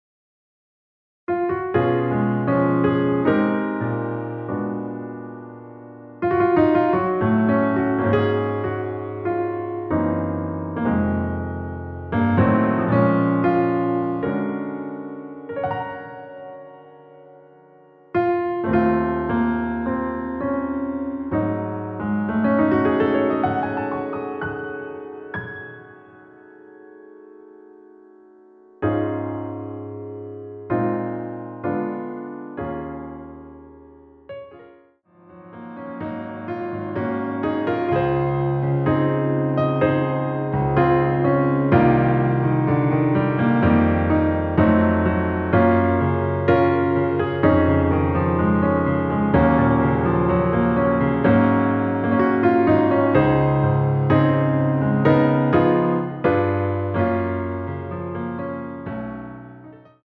원키에서(-3)내린 MR입니다.
Db
앞부분30초, 뒷부분30초씩 편집해서 올려 드리고 있습니다.
중간에 음이 끈어지고 다시 나오는 이유는